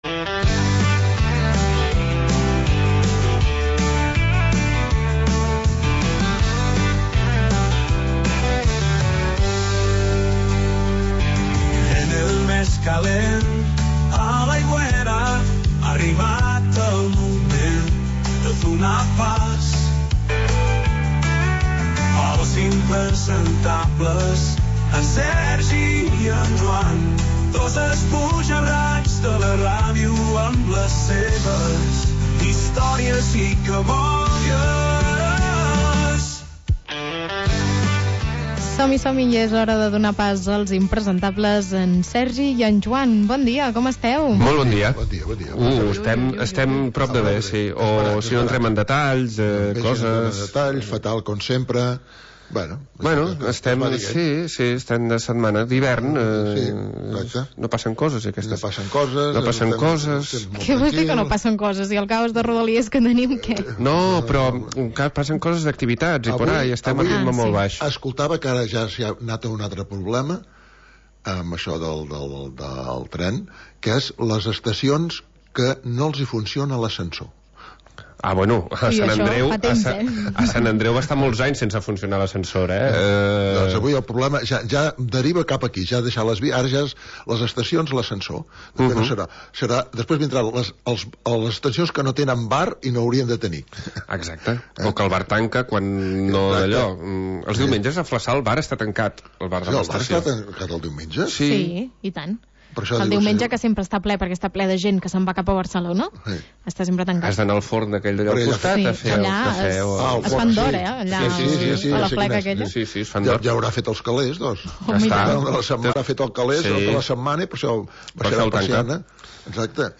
Històries i cabòries de dos esbojarrats de la ràdio.